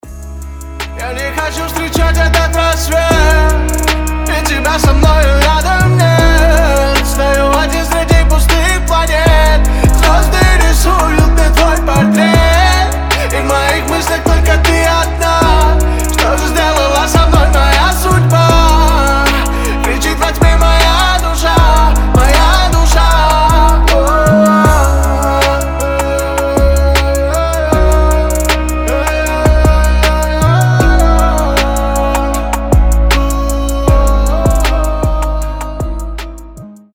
• Качество: 320, Stereo
красивые
лирика
грустные
мелодичные